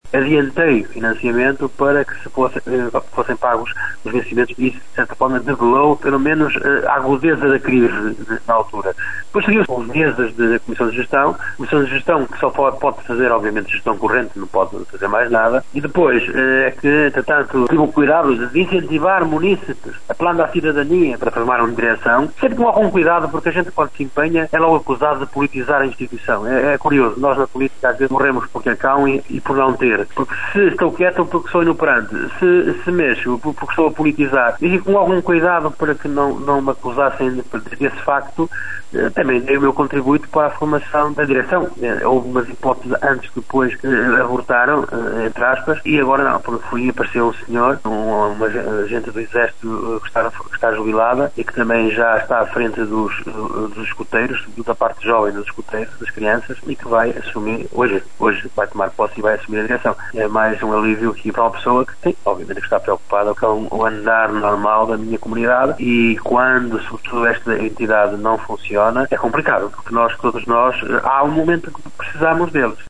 O autarca monçanense, Augusto Domingues, admite que interveio para ajudar a resolver a situação de uma das instituições mais importantes daquele concelho.